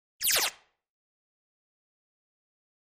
Beeps Sci-Fi Space Machine Beep 3